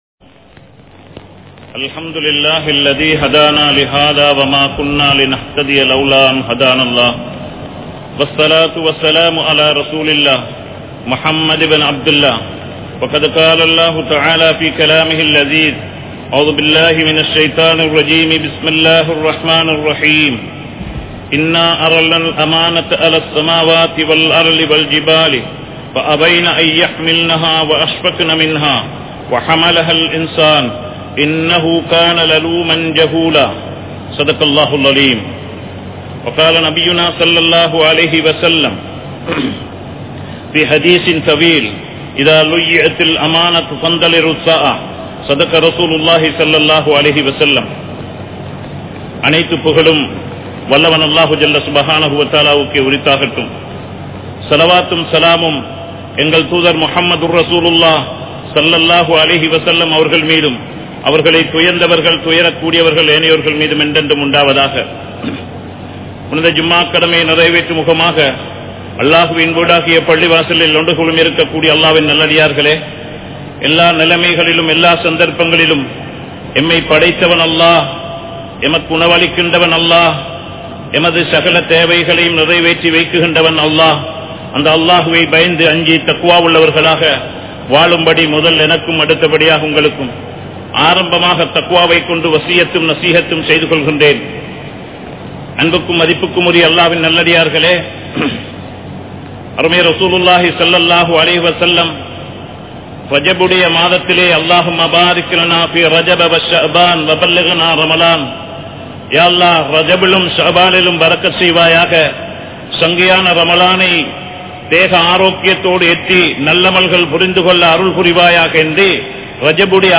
Amaanitham | Audio Bayans | All Ceylon Muslim Youth Community | Addalaichenai
Colombo 03, Kollupitty Jumua Masjith